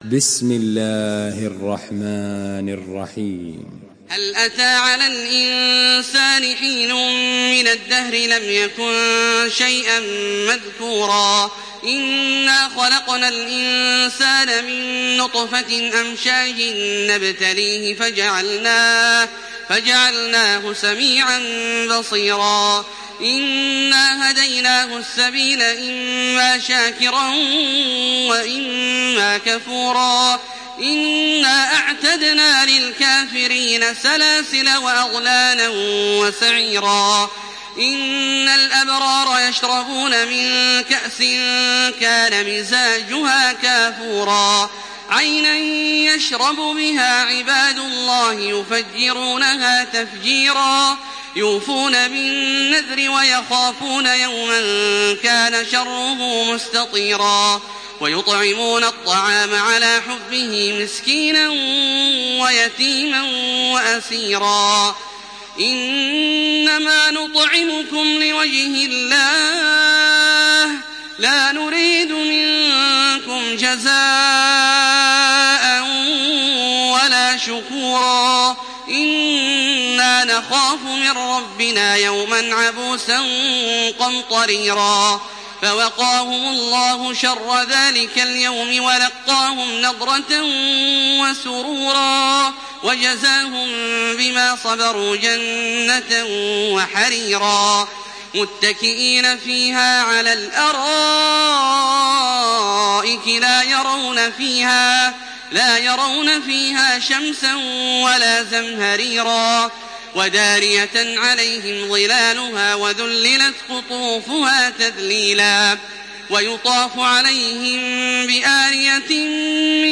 Surah Al-Insan MP3 in the Voice of Makkah Taraweeh 1428 in Hafs Narration
Listen and download the full recitation in MP3 format via direct and fast links in multiple qualities to your mobile phone.
Murattal